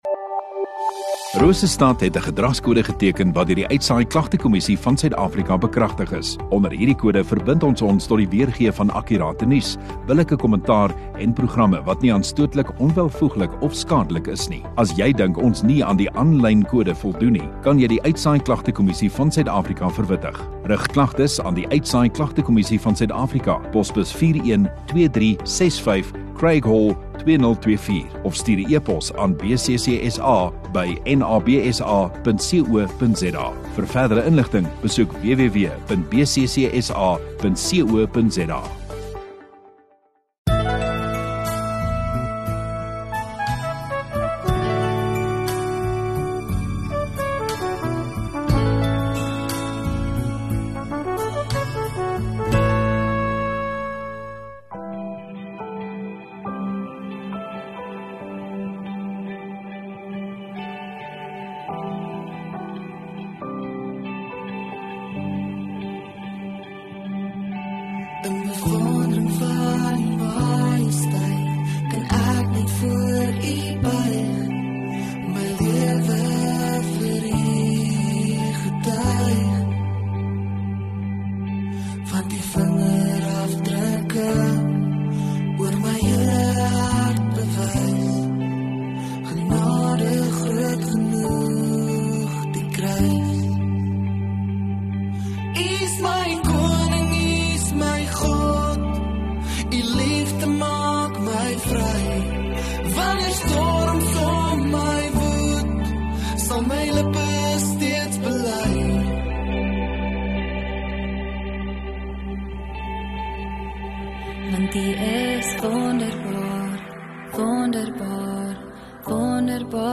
20 Apr Sondagaand Erediens